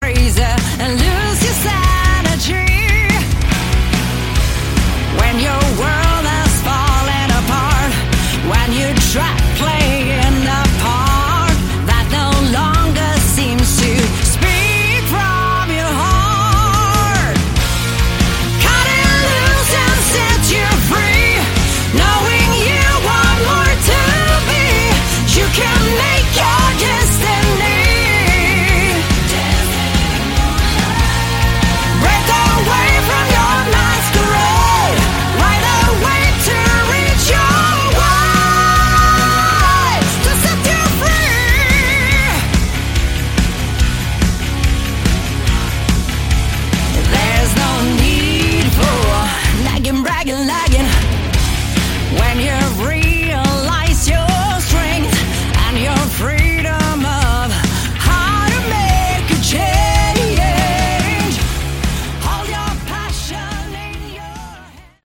Category: Melodic Rock
keyboard/Hammond organ